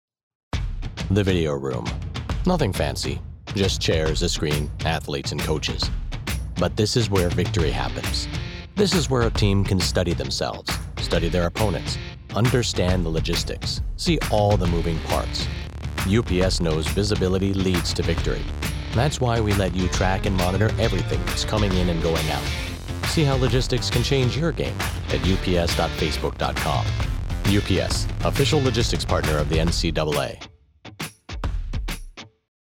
Guy next door, father, powerful, calming, general american Speech with quick turnaround
UPS TV sample commercial